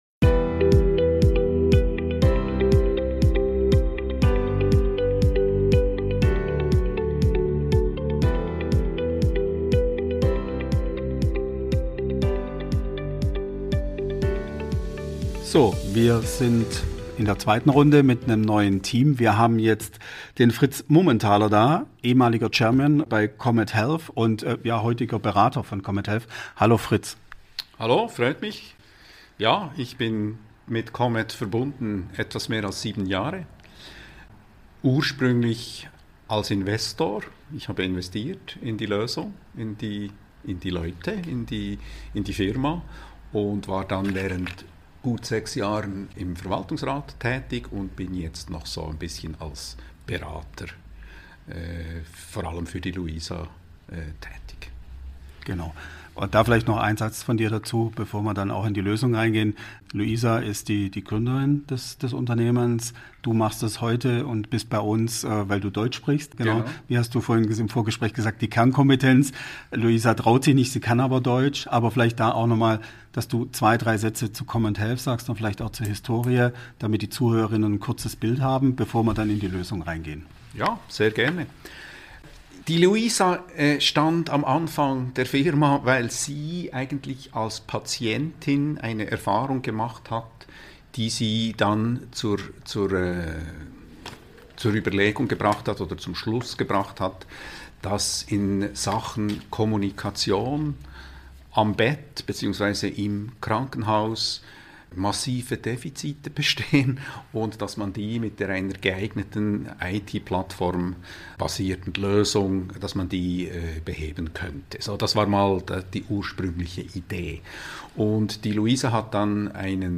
Wir waren mit Rocketing Healthcare unterwegs auf der IFAS 2024 in Zürich.